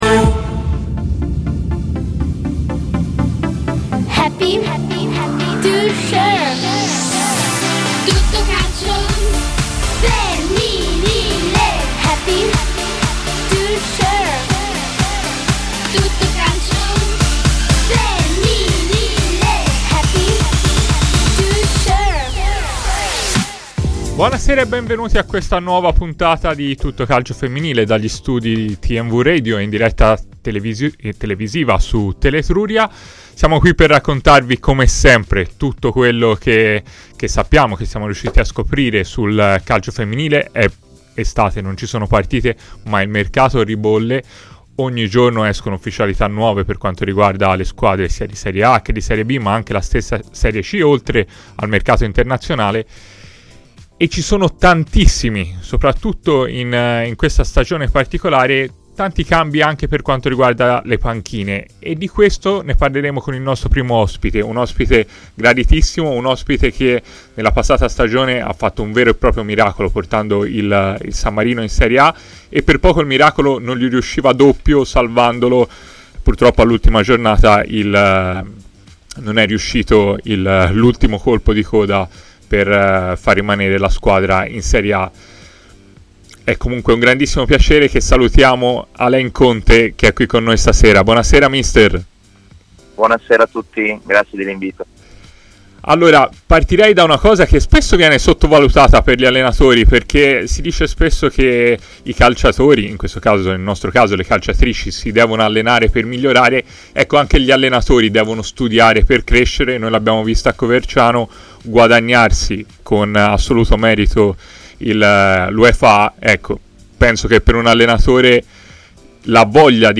Ospiti telefonici
© registrazione di TMW Radio